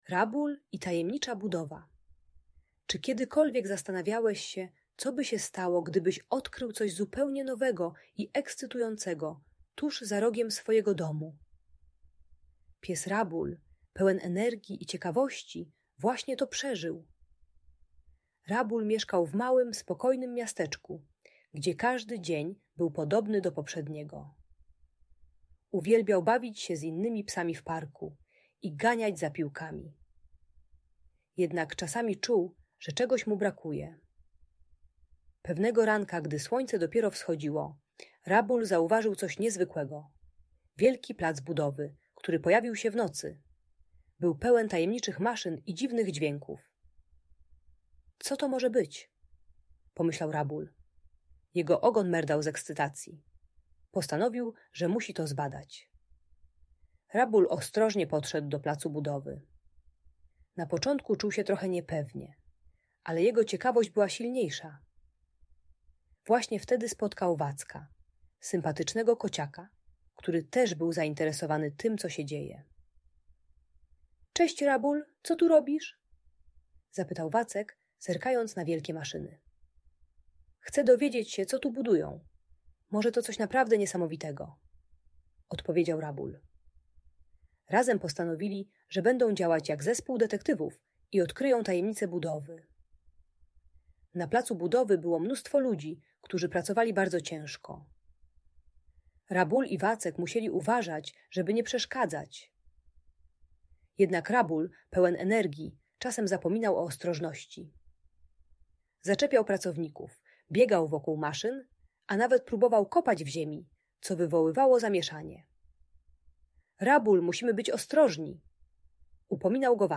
Rabul i Tajemnicza Budowa - Bunt i wybuchy złości | Audiobajka